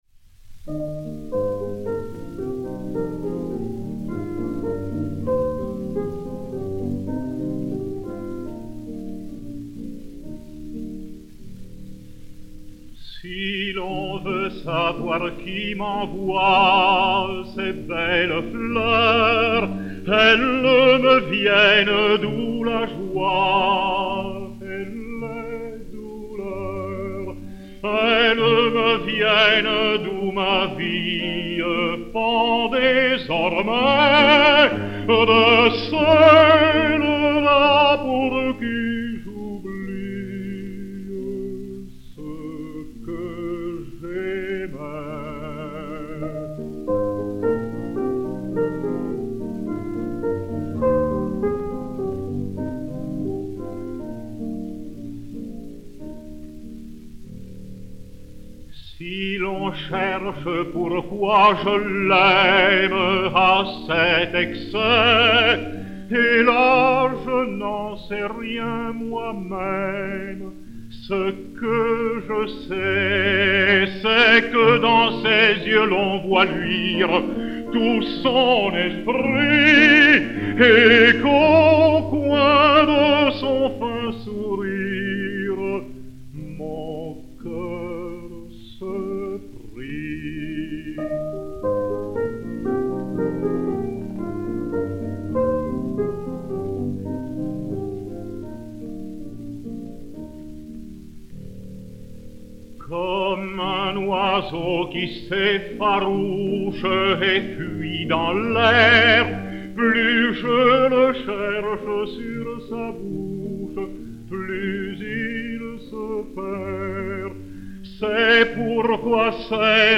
Mélodie
piano